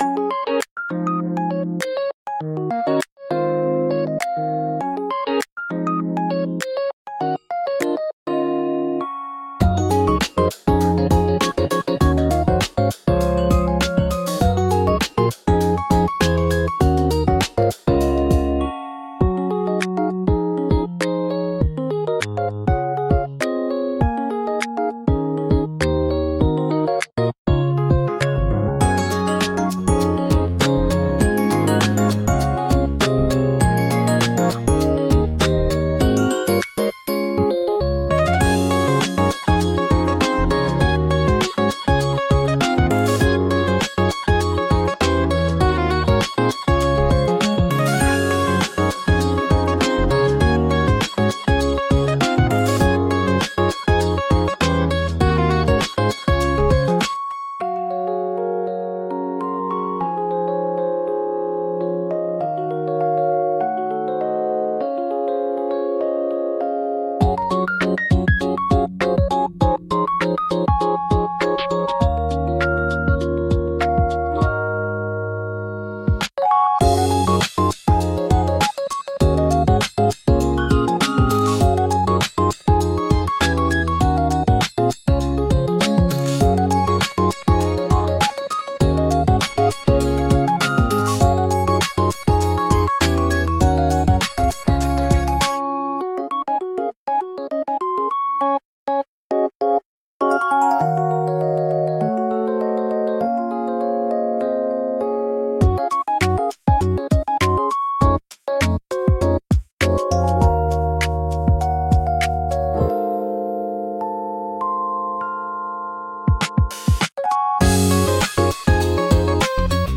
穏やかで可愛い、はじまりのBGM